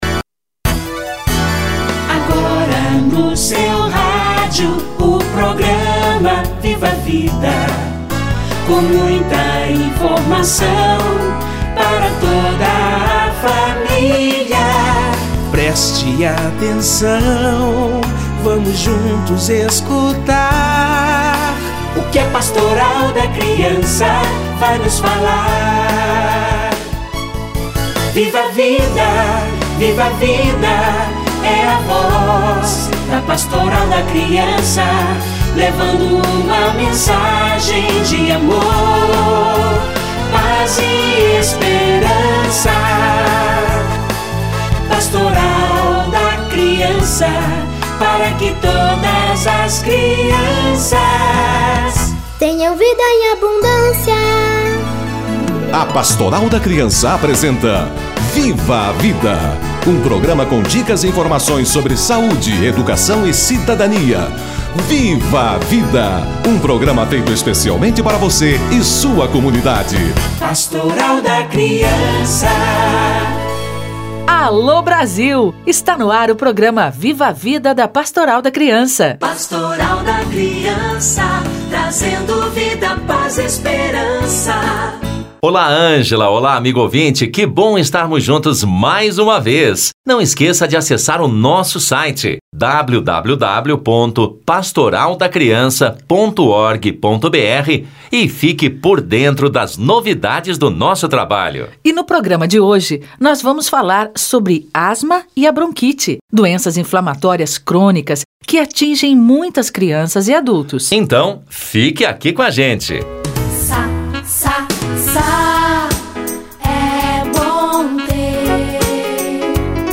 Asma e bronquite - Entrevista